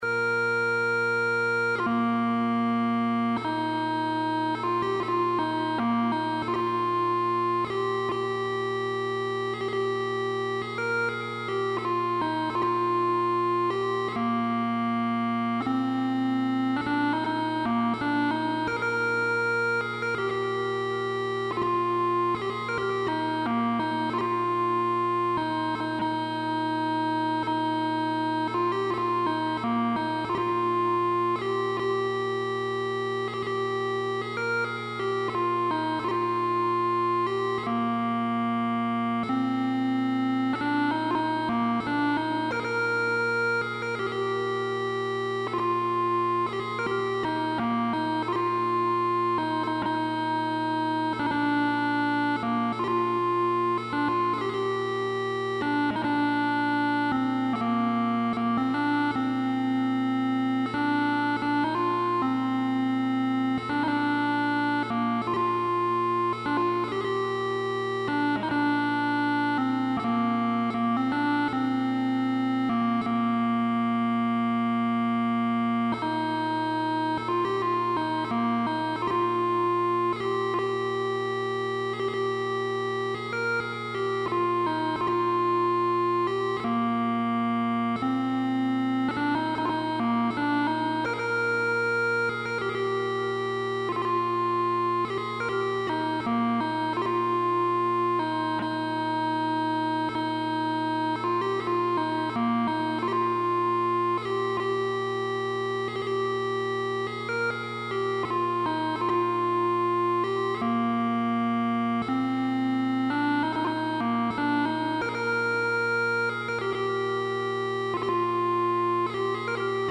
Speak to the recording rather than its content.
using a Creative Lab Surround Mixer for effects